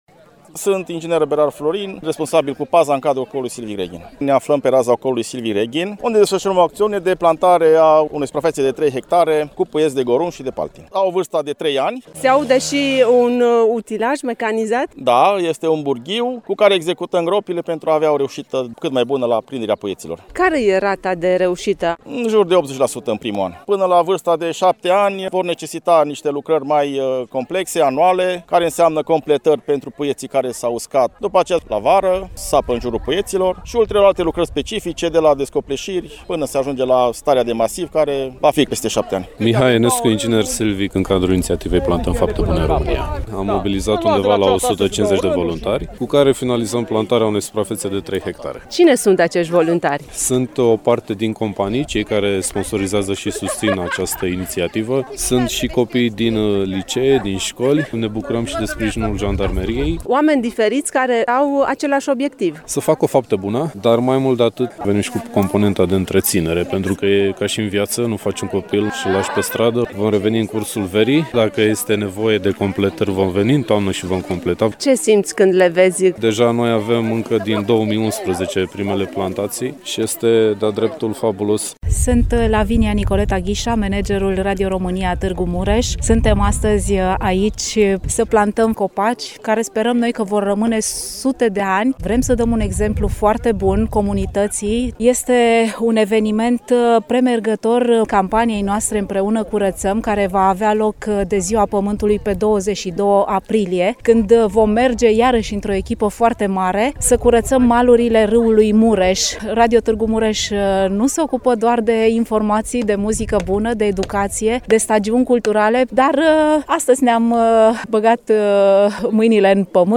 Detalii în reportajul